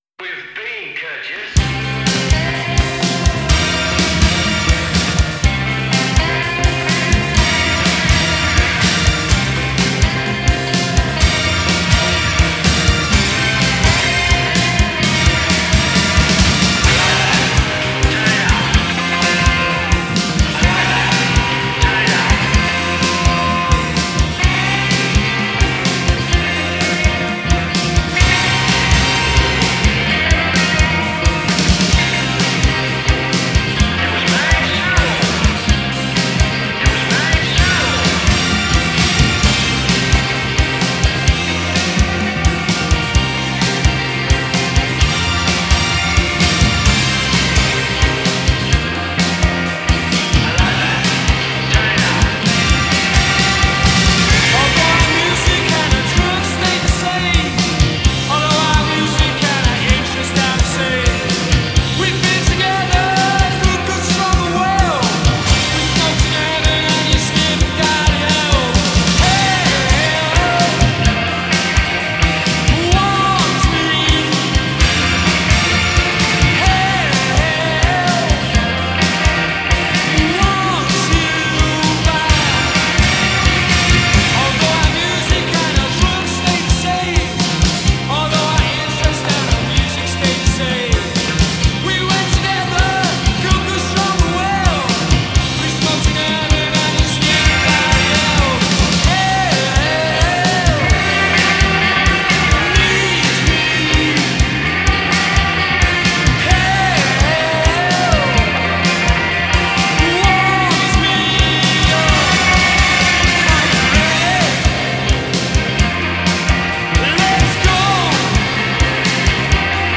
the swirling, unstoppable shouty house